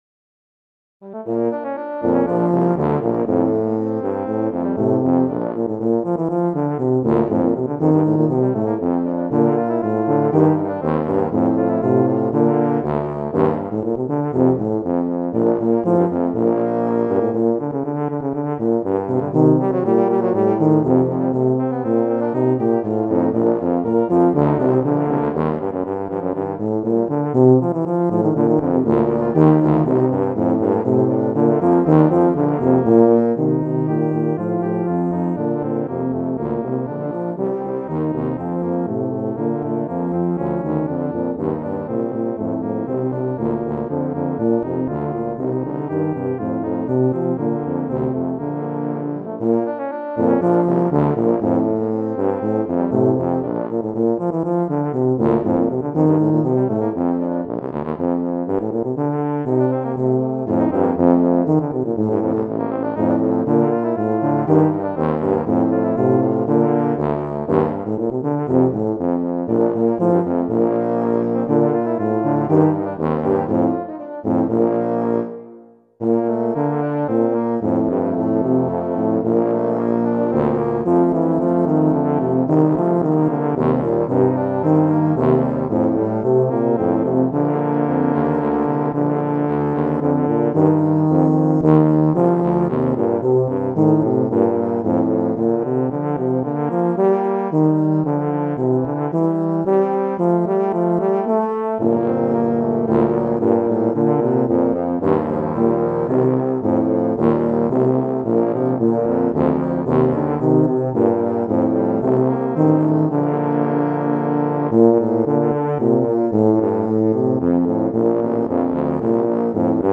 Voicing: Low Brass Trio